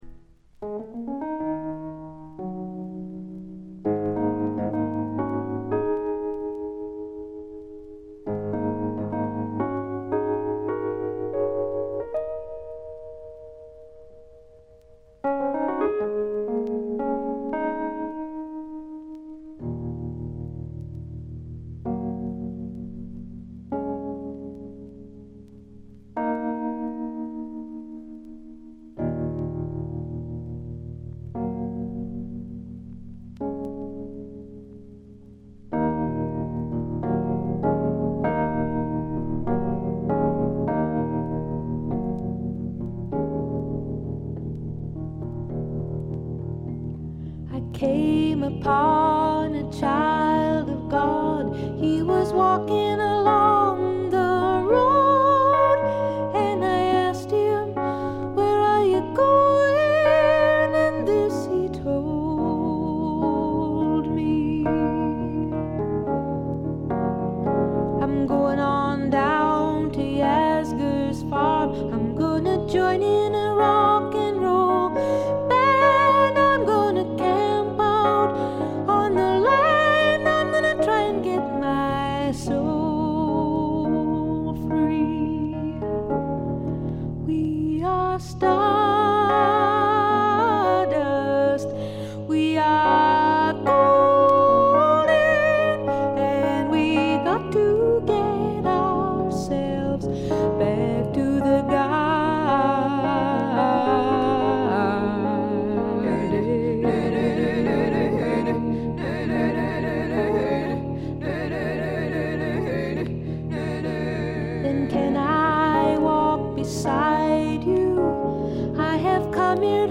ところどころで軽微なチリプチ。
美しいことこの上ない女性シンガー・ソングライター名作。
試聴曲は現品からの取り込み音源です。